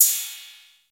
Open Hats
OpHat (Atl).wav